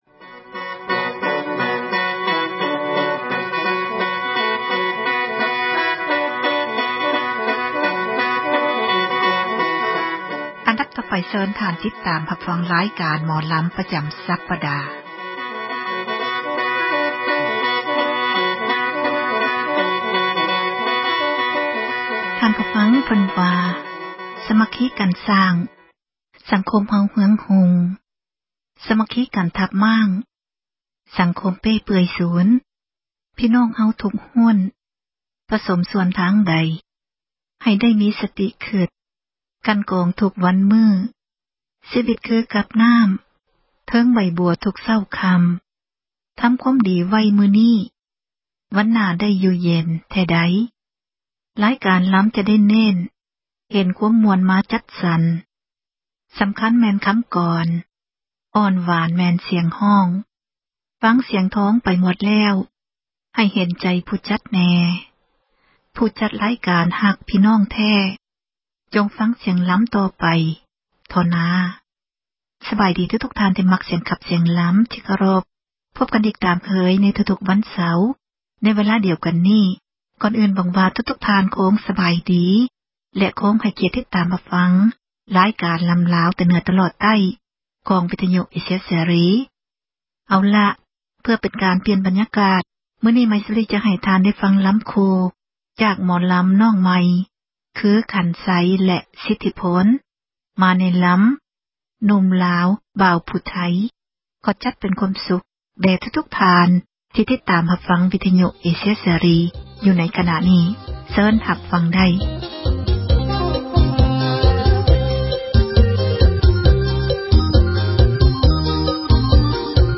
ຣາຍການ ໝໍລຳລາວ ປະຈຳ ສັປດາ ຈະນໍາເອົາ ສິລປະ ການຂັບລໍາ ທີ່ເປັນມູນ ມໍຣະດົກ ຂອງລາວ ໃນແຕ່ລະ ຊົນເຜົ່າ ແຕ່ລະ ພາກພື້ນເມືອງ ທີ່ເຮົາຄົນລາວ ຈະຕ້ອງ ສົ່ງເສີມ ແລະ ອະນຸຮັກ ຕໍ່ໄປ.